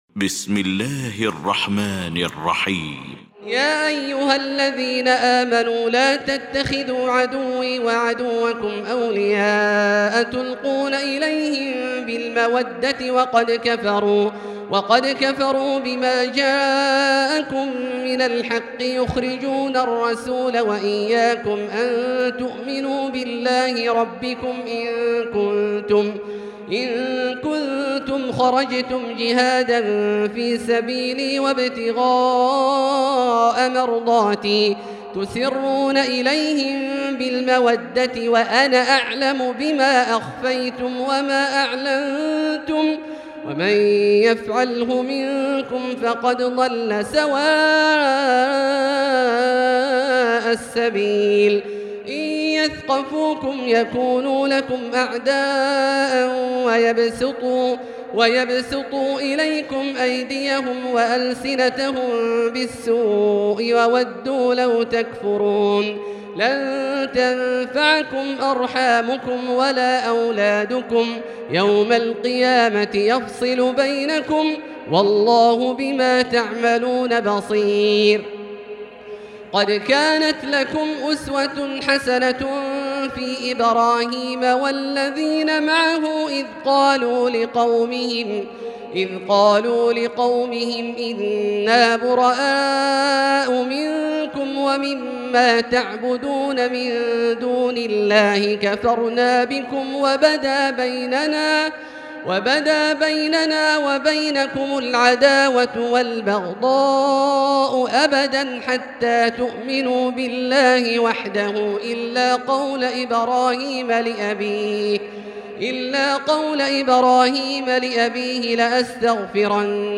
المكان: المسجد الحرام الشيخ: فضيلة الشيخ عبدالله الجهني فضيلة الشيخ عبدالله الجهني الممتحنة